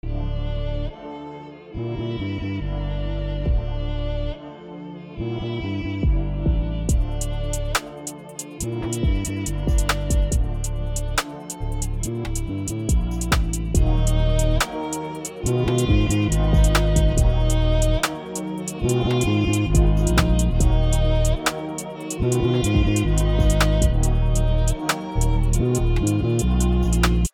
קבלו קטע קצר של ביט כינור שעיבדתי (רק במלחמה יש זמן לזה…)